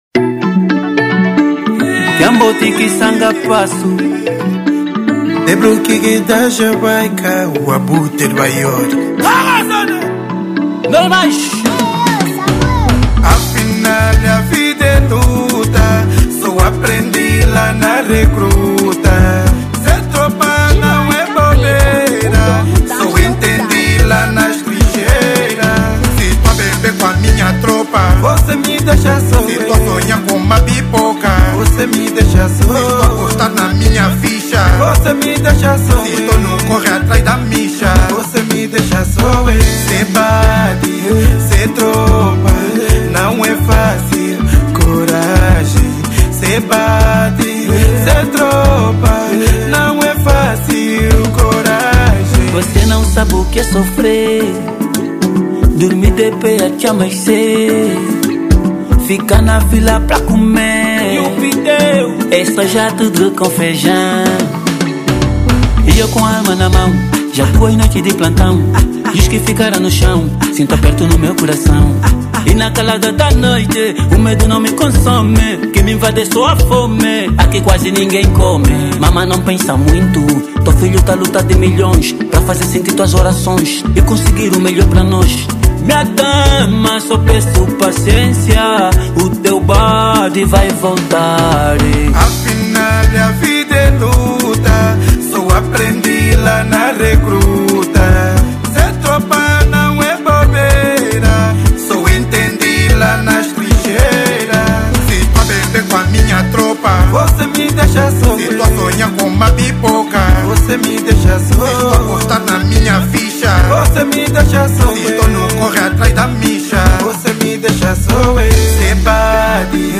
Kuduro 2025